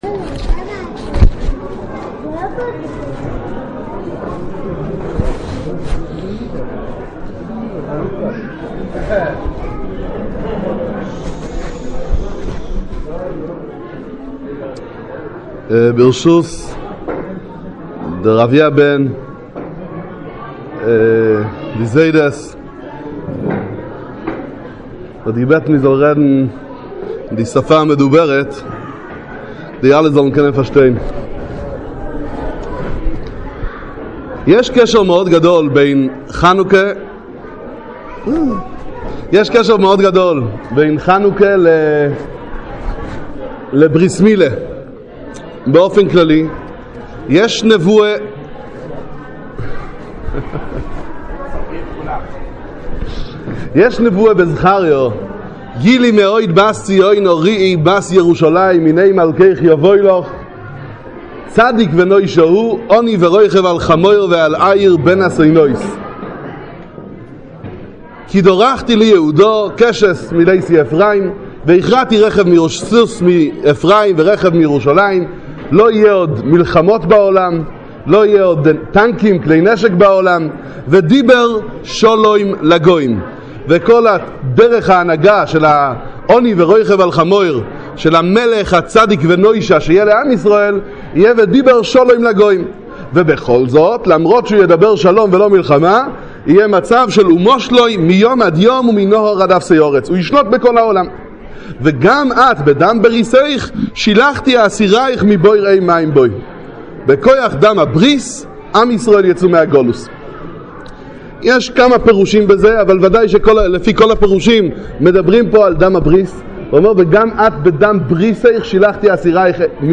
קידש ידיד מבטן - דרשה לברית מילה בחנוכה